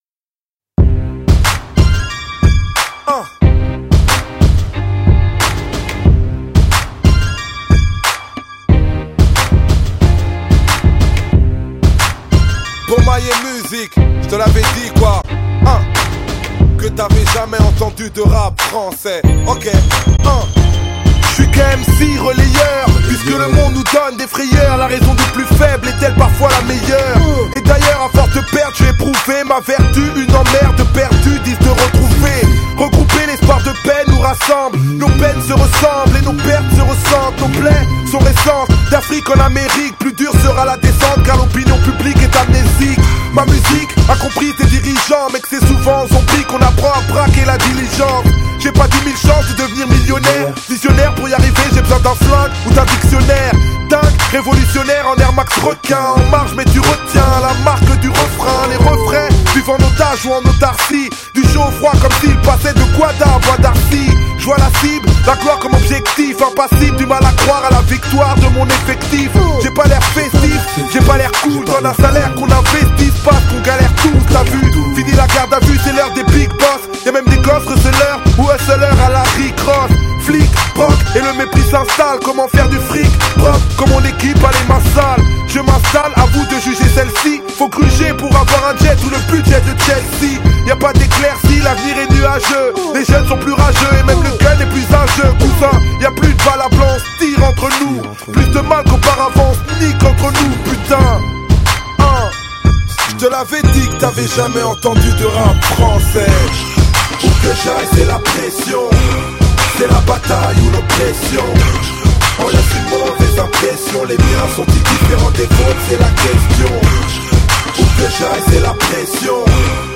Genre: French Rap